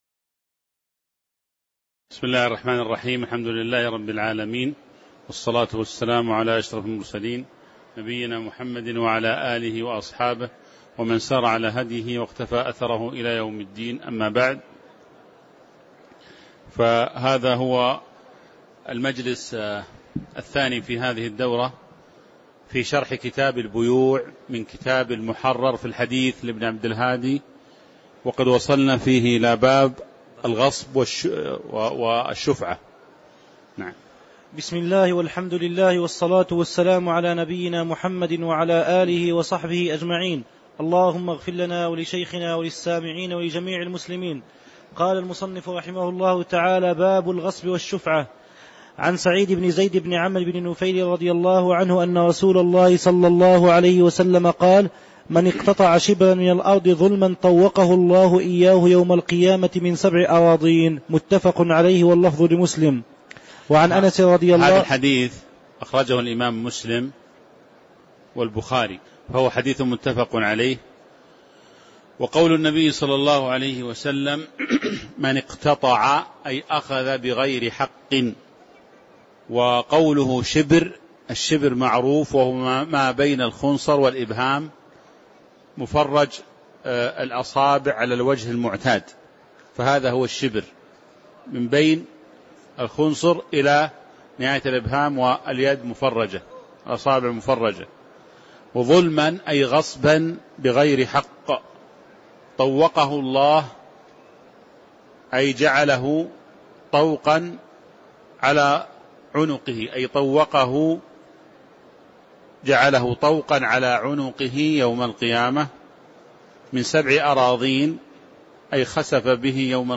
تاريخ النشر ١٥ شوال ١٤٤٦ هـ المكان: المسجد النبوي الشيخ